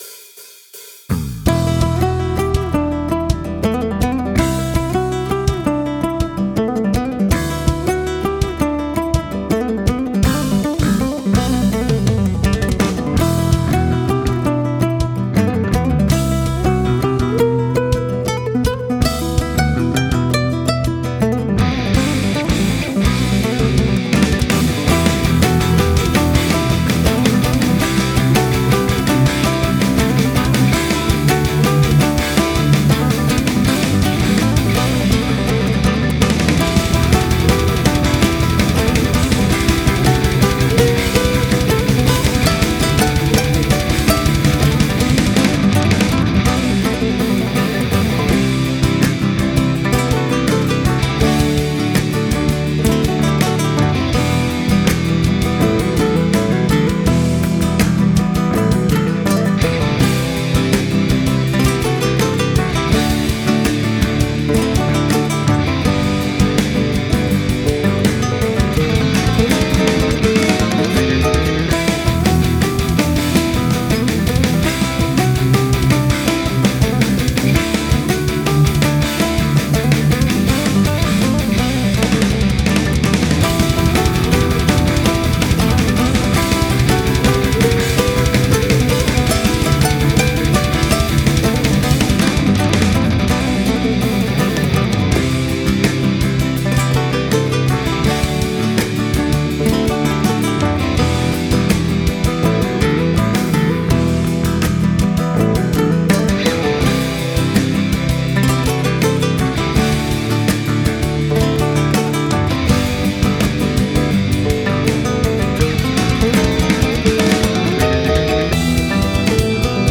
Genre: Guitar Virtuoso, Speed Metal, Flamenco